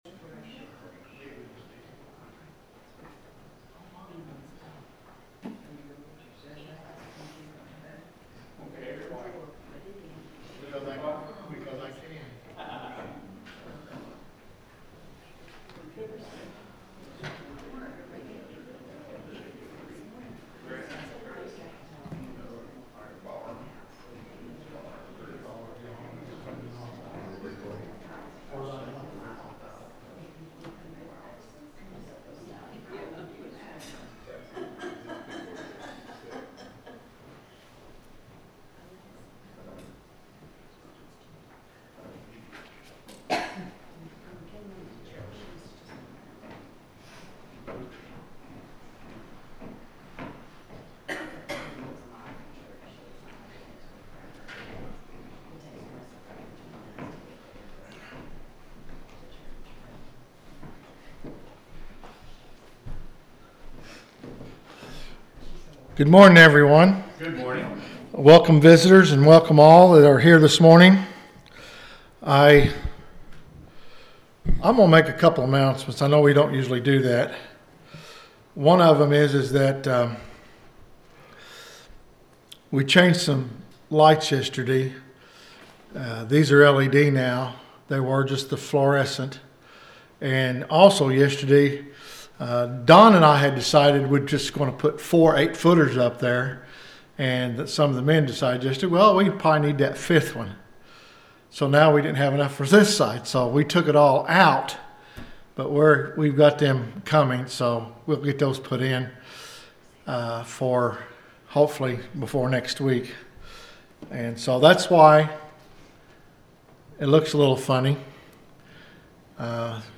The sermon is from our live stream on 10/12/2025